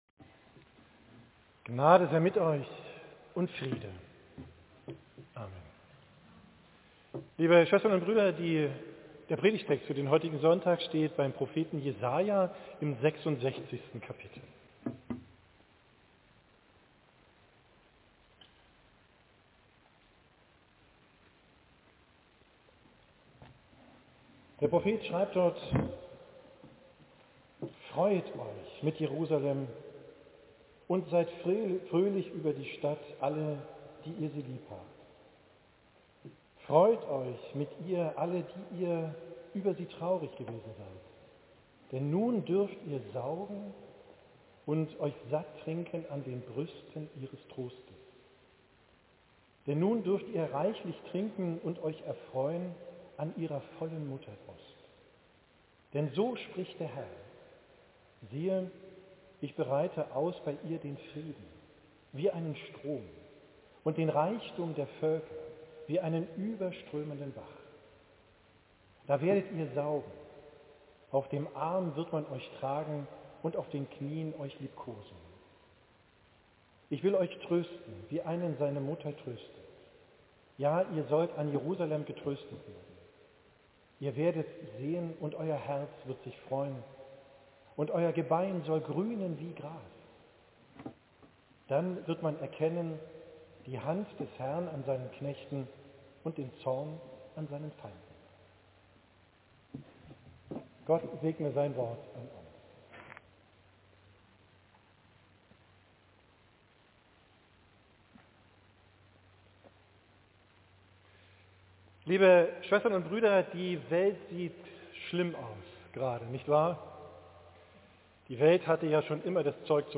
Predigt vom Sonntag Lätare, 15.